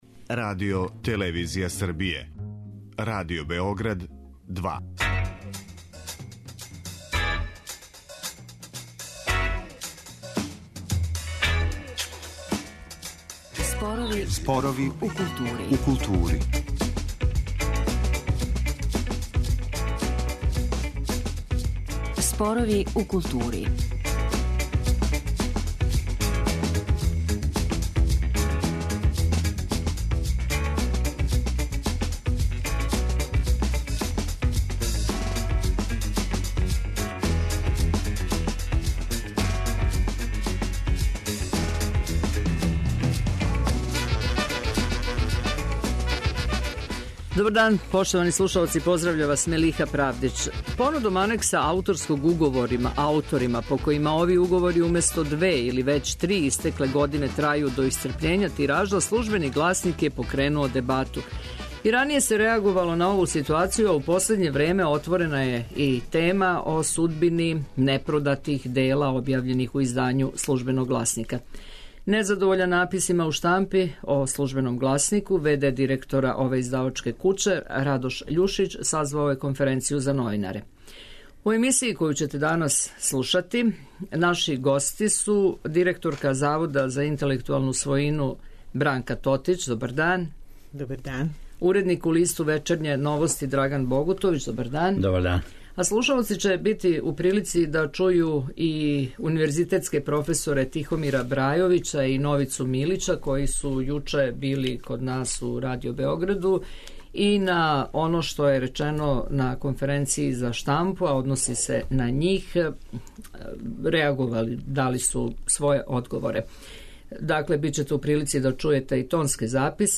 Слушаоци ће бити у прилици да чују и тонске записе са одржане конференције за новинаре у Службеном гласнику .